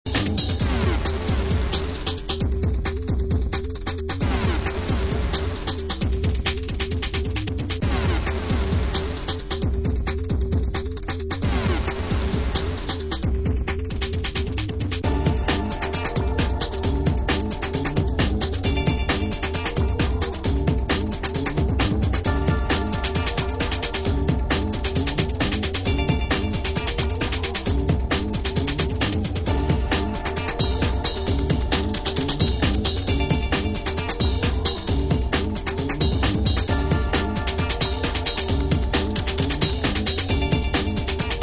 Tercera maqueta con estilo bailable y ritmos rápidos.